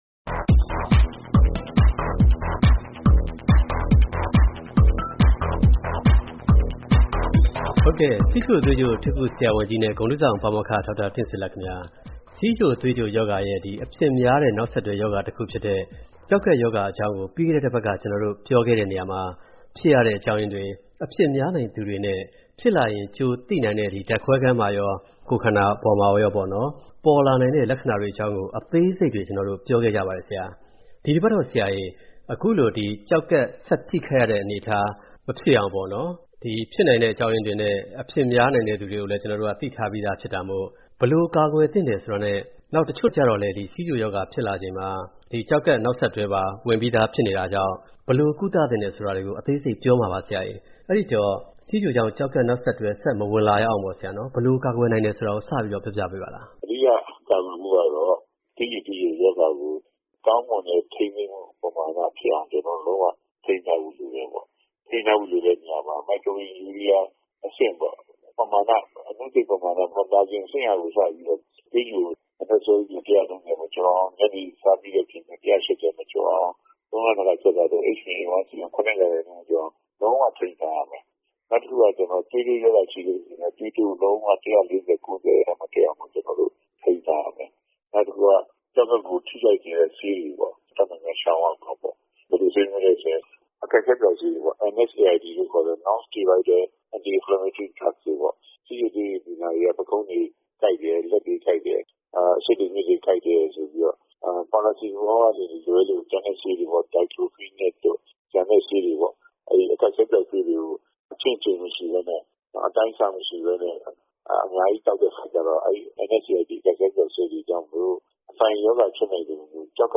ဆက်သွယ်မေးမြန်း ဆွေးနွေးတင်ပြထားပါတယ်။